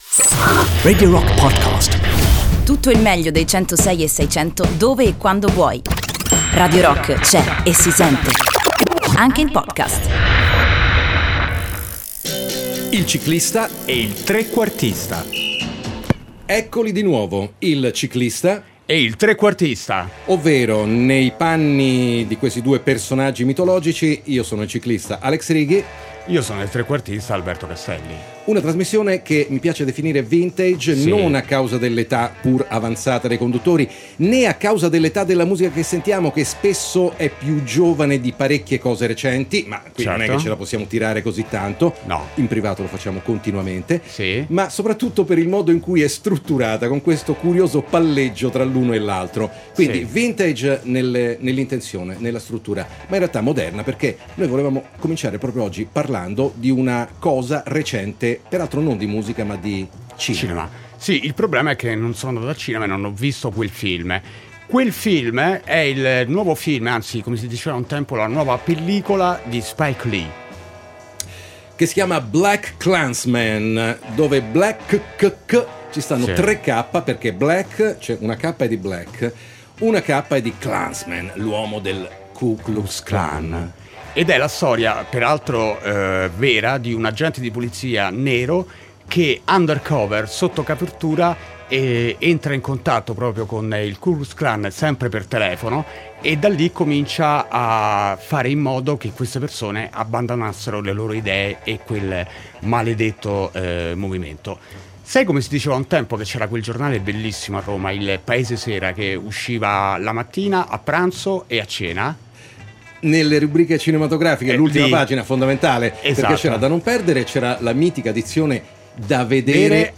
Un programma radiofonico di canzoni, storie e altre avventure più o meno improbabili.